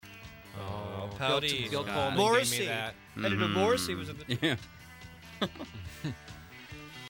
Morrissey mentioned in Kids In The Hall DVD commentary